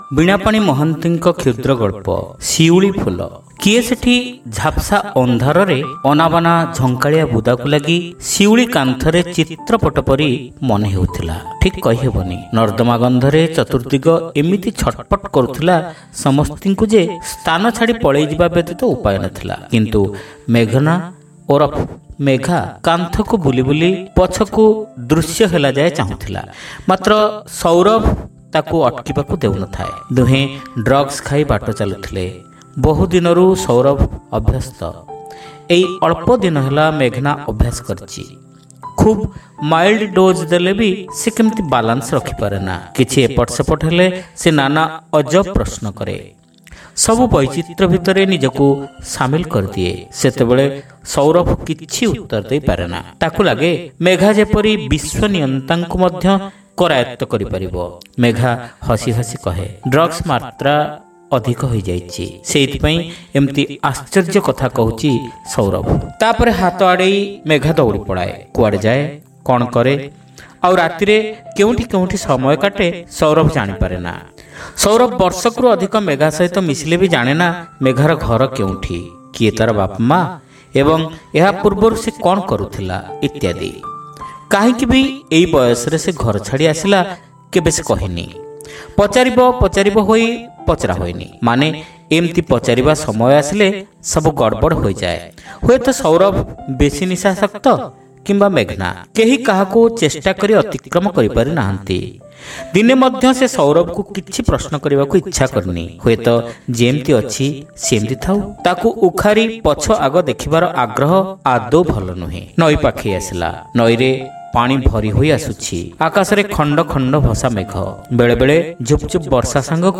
Audio Story : Shiuliphula (Part-1)